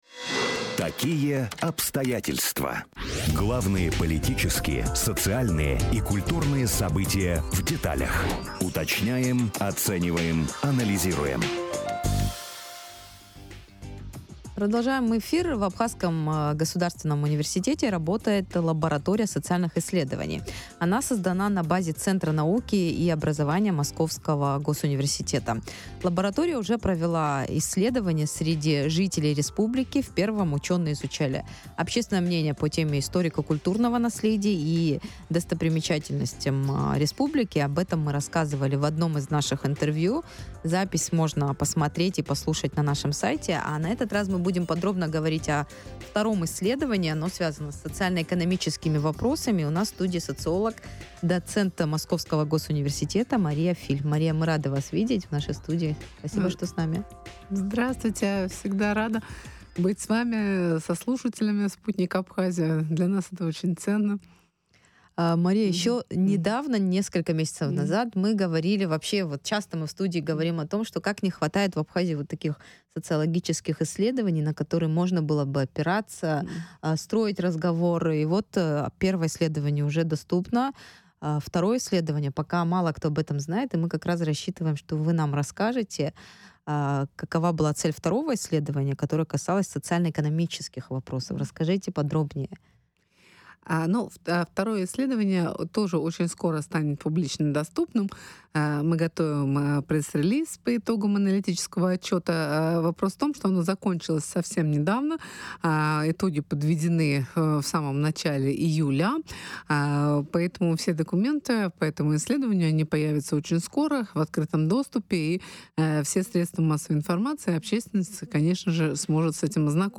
О результатах в эфире радио Sputnik... 09.07.2025, Sputnik Абхазия